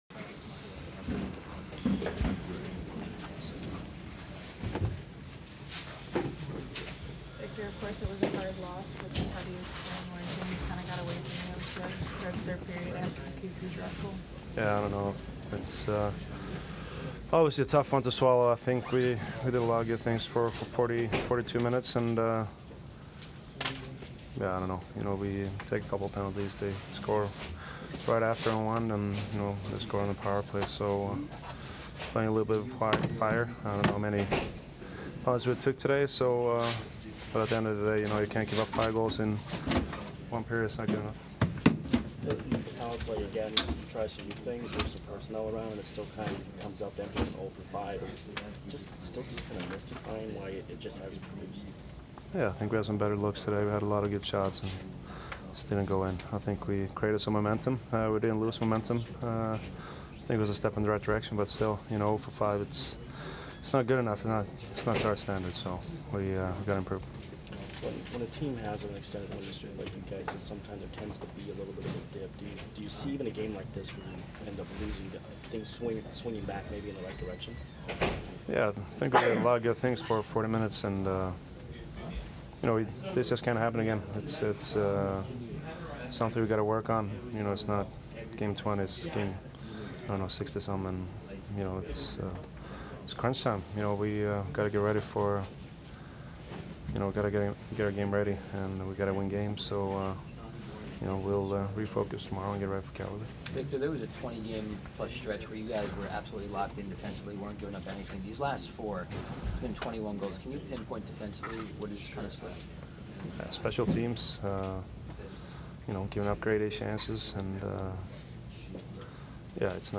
Victor Hedman post-game 2/27